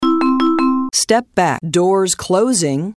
Almost Every 7k Announcement